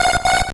Cri de Natu dans Pokémon Or et Argent.